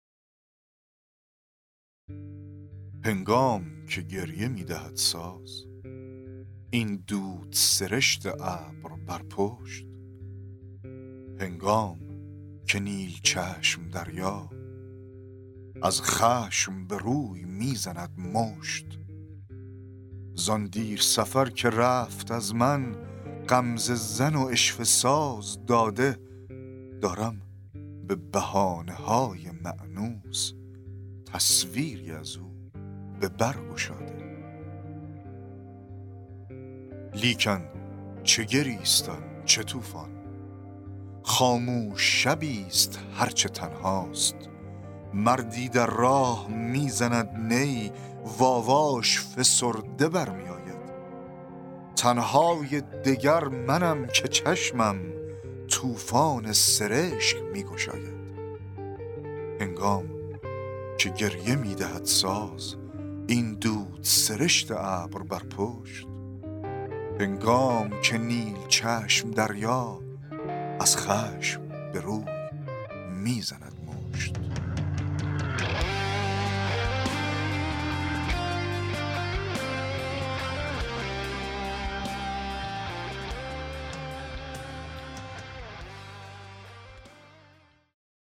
دکلمه و تحلیل شعر, شعرهای نیما یوشیج